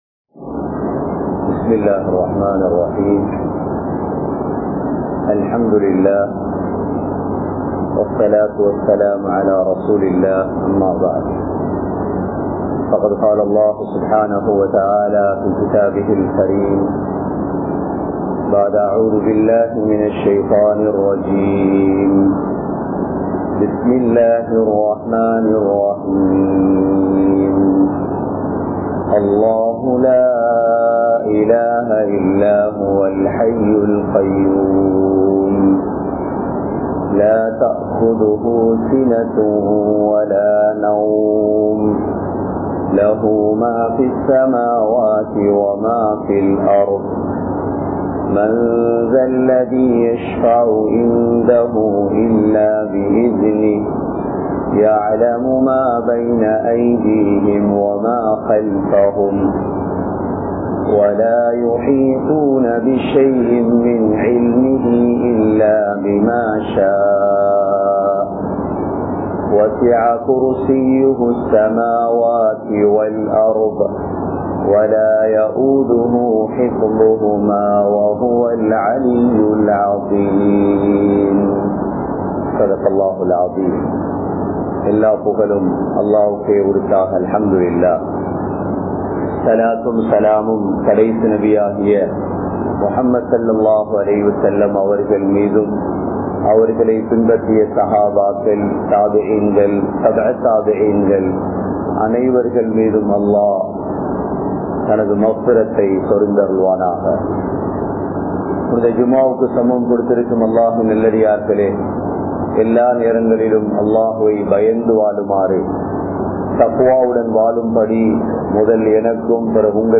Aayathul Qursien Atputham (ஆயத்துல் குர்ஸிய்யின் அற்புதம்) | Audio Bayans | All Ceylon Muslim Youth Community | Addalaichenai
Colombo 03, Kollupitty Jumua Masjith